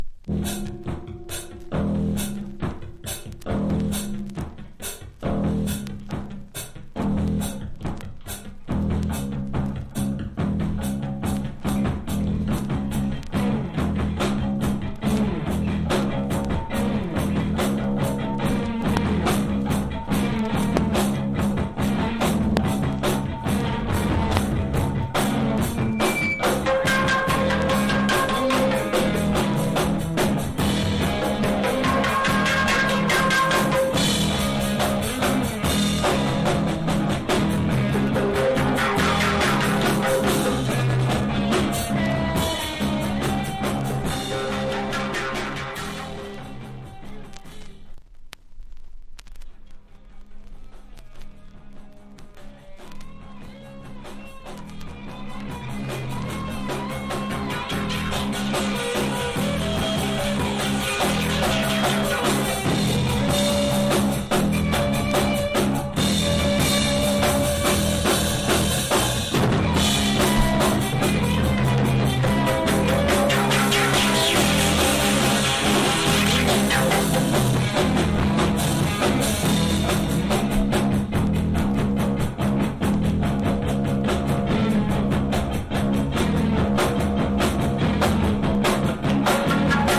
# NEW WAVE# POST PUNK# 80’s ROCK / POPS# ELECTRO POP